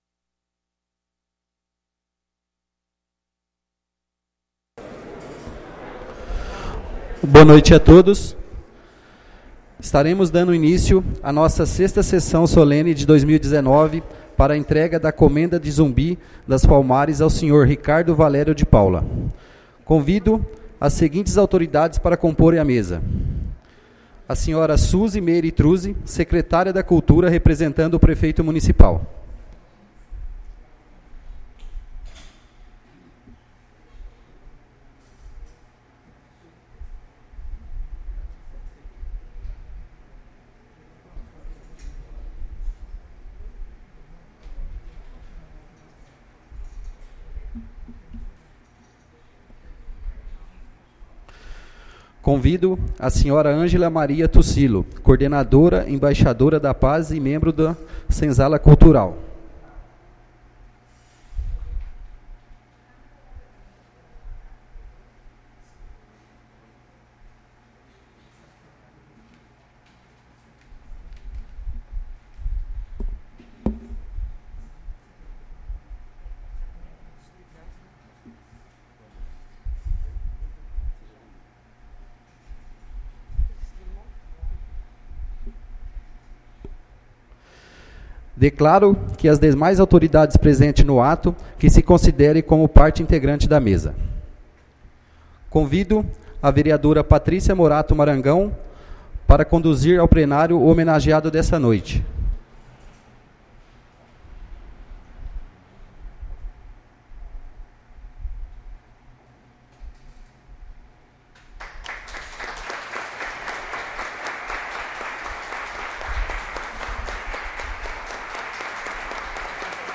Sessões Solenes